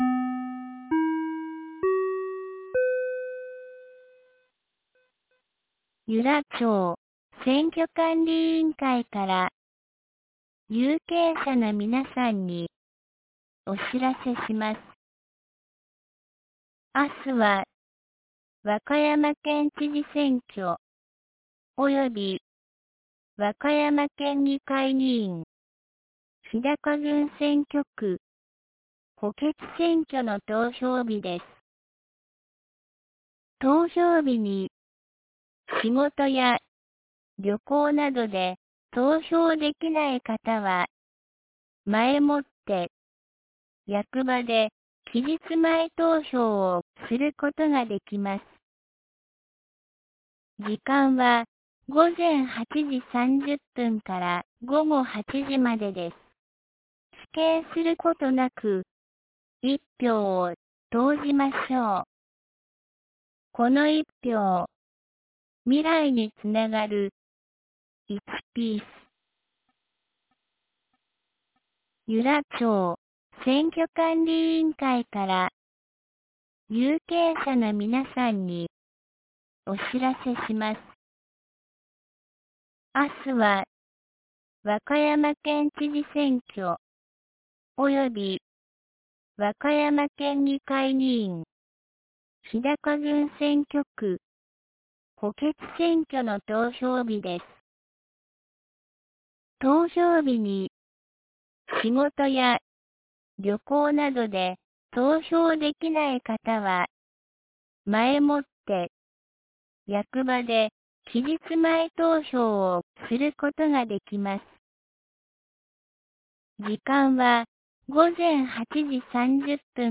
2025年05月31日 07時52分に、由良町から全地区へ放送がありました。